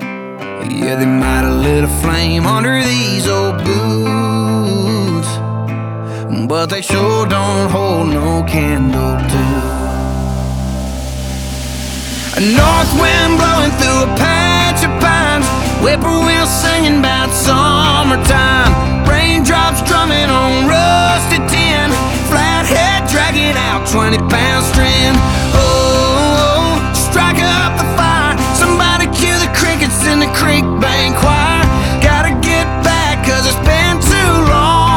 Жанр: Кантри